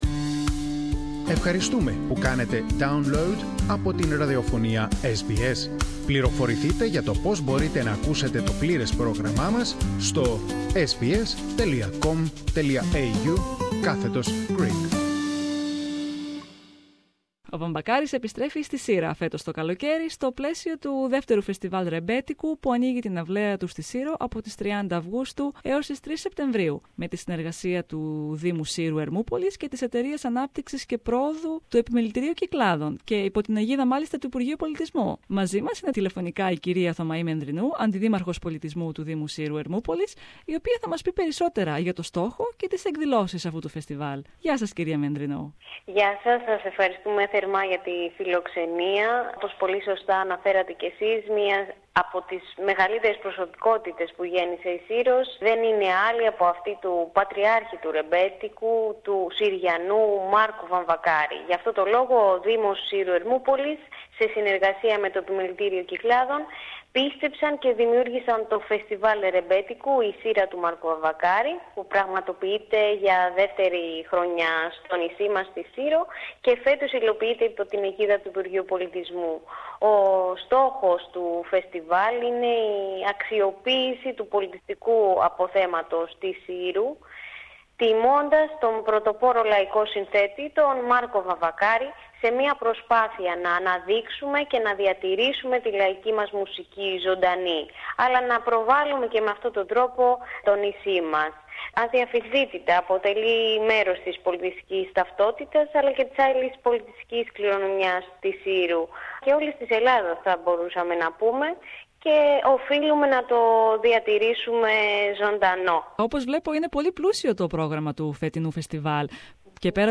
μίλησε με την Αντιδήμαρχο Πολιτισμού του Δήμου Σύρου - Ερμούπολης, κα Θωμαή Μενδρινού, για το στόχο και τις εκδηλώσεις του Φεστιβάλ.